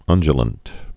(ŭnjə-lənt, ŭndyə-, -də-)